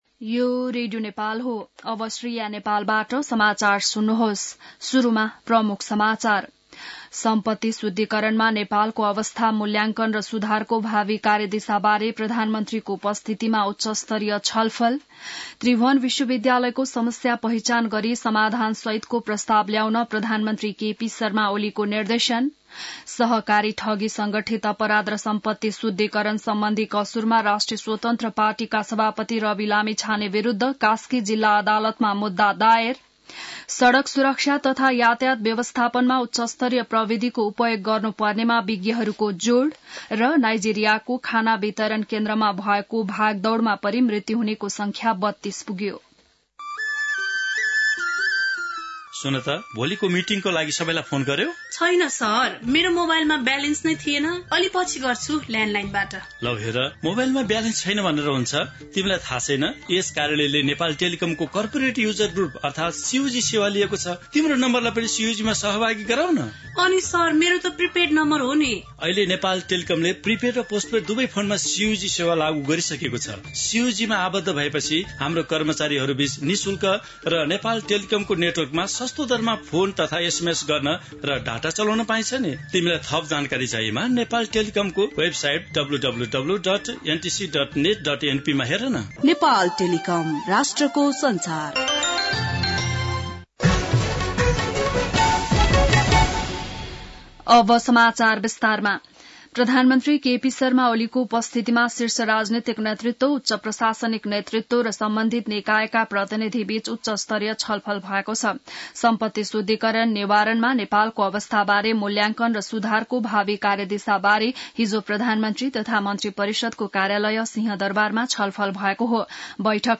बिहान ७ बजेको नेपाली समाचार : ९ पुष , २०८१